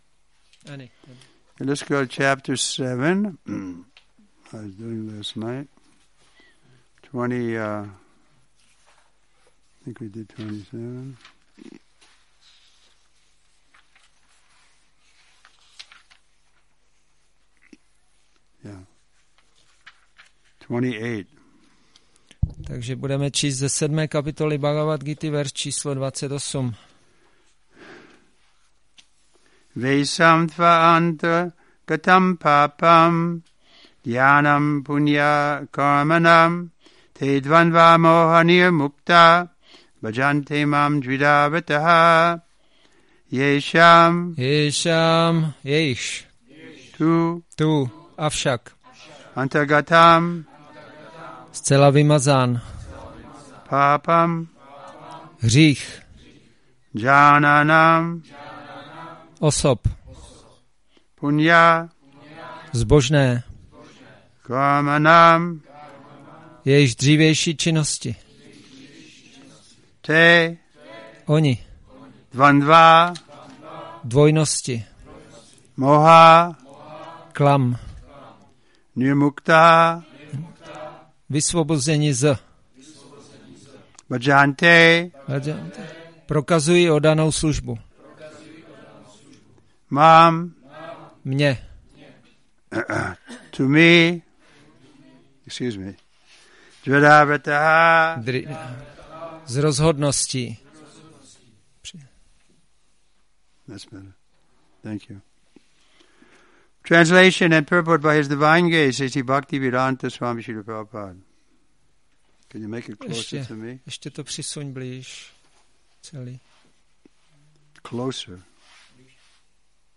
Přednáška BG-7.28